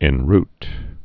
(ĕn-rt, -rt)